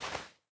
Minecraft Version Minecraft Version snapshot Latest Release | Latest Snapshot snapshot / assets / minecraft / sounds / block / powder_snow / break6.ogg Compare With Compare With Latest Release | Latest Snapshot